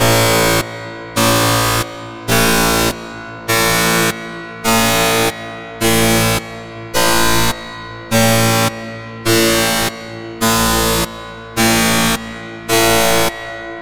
Sound Alert In Hall